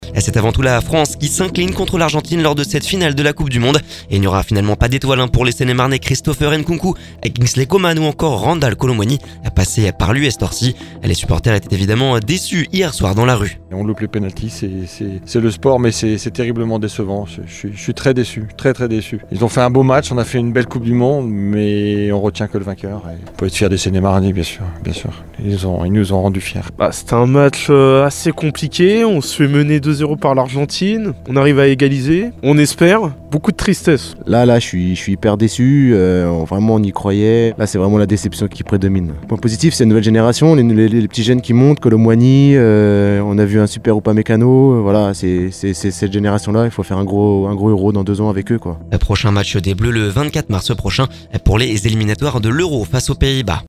Il n’y aura pas finalement d’étoiles pour les Seine-et-Marnais Christopher Nkunku, Kingsley Coman et Randal Kolo Muani passé par l’US Torcy. Les supporters étaient évidemment déçu hier soir dans la rue…